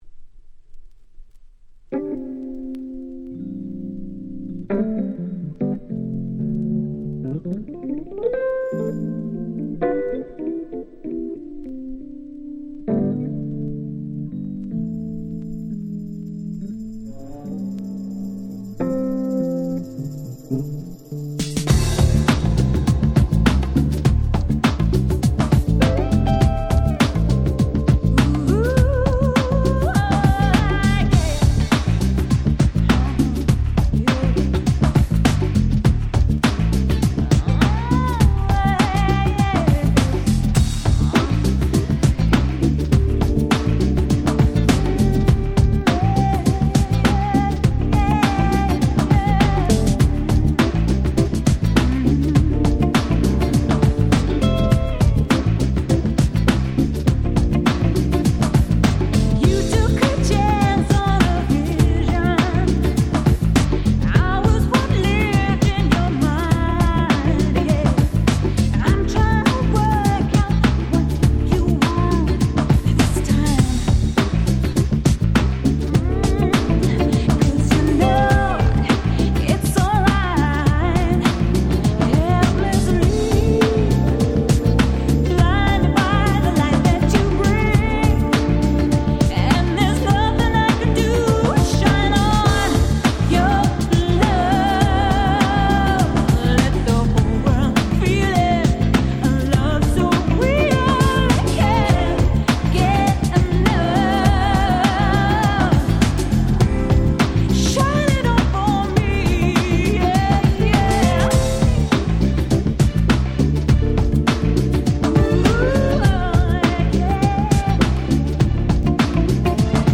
92' Very Nice UK R&B / UK Street Soul Album !!
もう教科書通りの爽やかで素敵なUK Soulしか入っておりません。